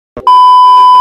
No Signal Sound Button - Bouton d'effet sonore